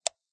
click_plastic_single.ogg